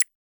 Modern Click 3.wav